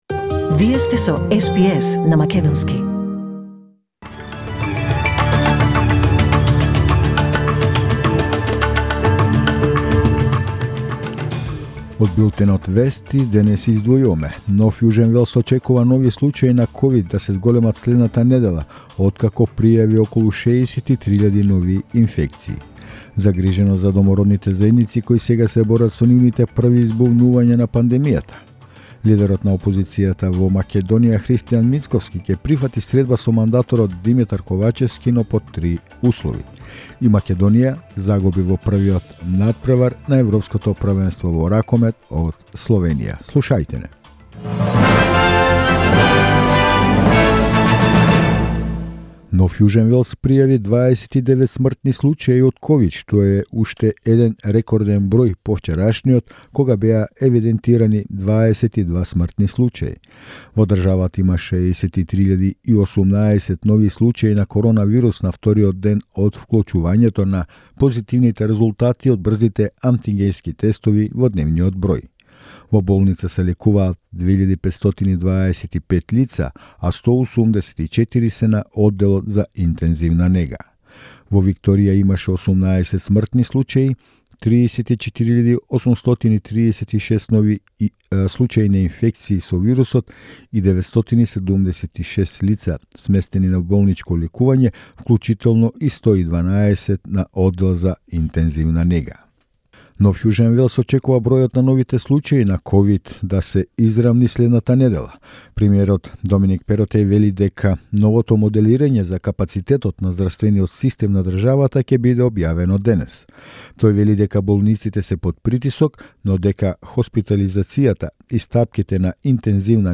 SBS News in Macedonian 14 January 2022